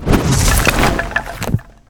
Summon_Magic.ogg